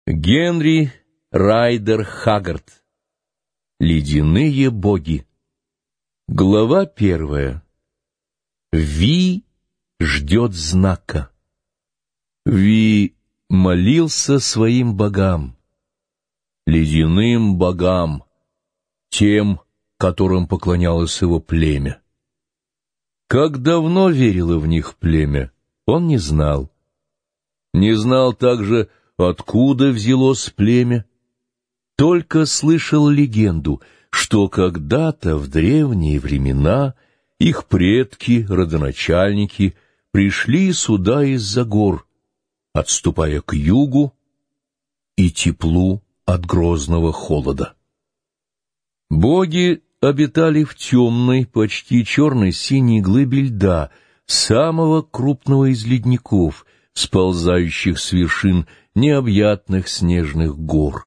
Аудиокнига Ледяные боги | Библиотека аудиокниг